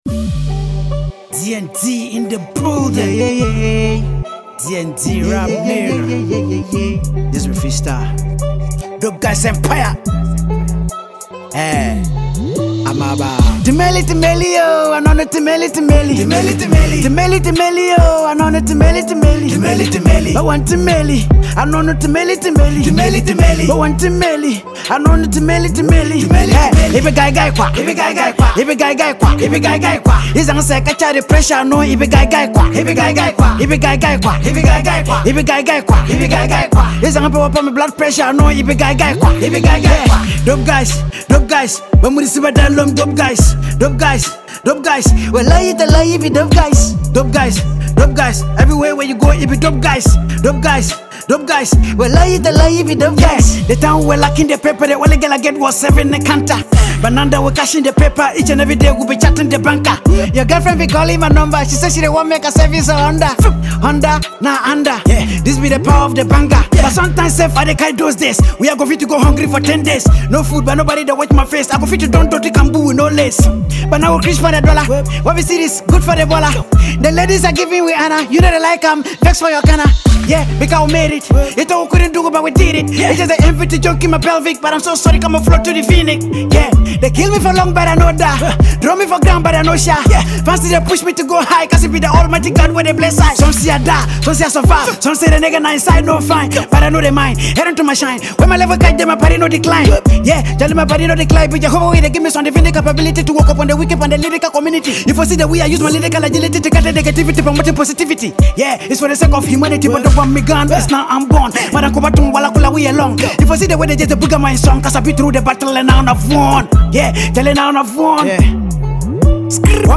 lyrically charged Freestyle
drill freestyle
it is driven by a catchy hook